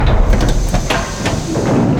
StartDocking.wav